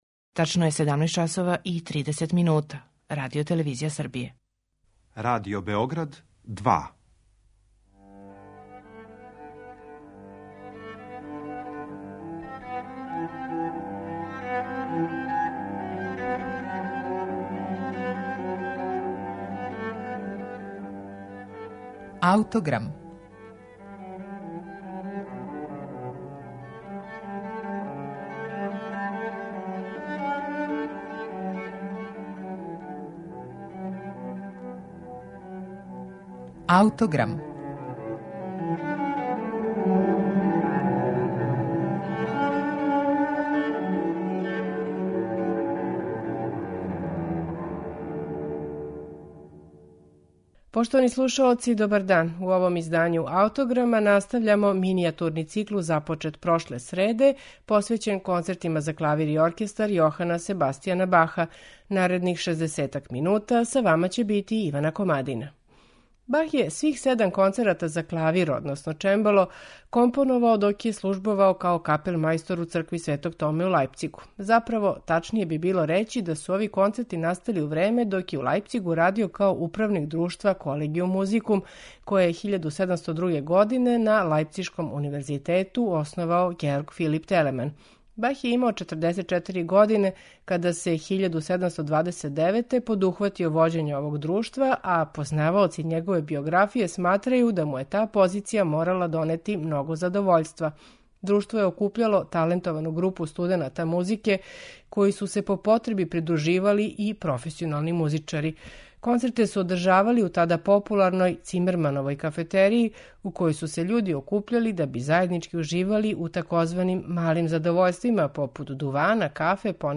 Концерти за клавир и оркестар број 3, 5, 6 и 7
као пијаниста и диригент